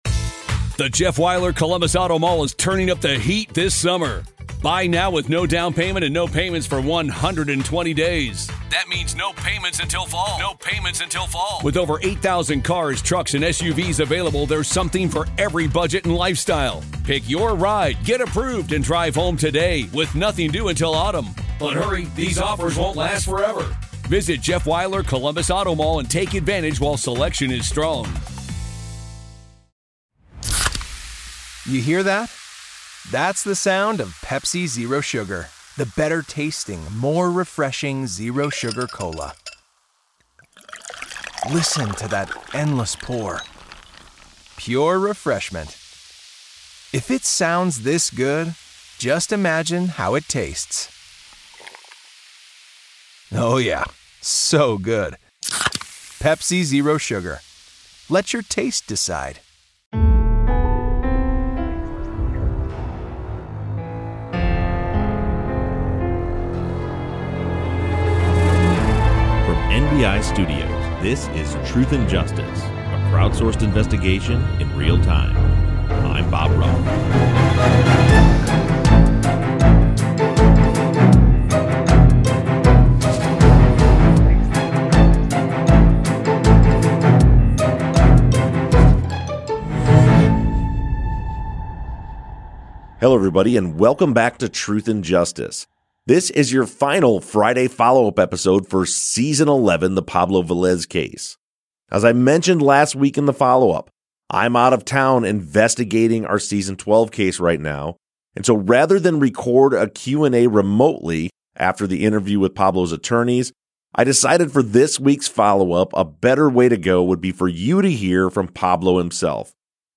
True Crime, Documentary, Society & Culture